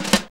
JAZZ FILL 10.wav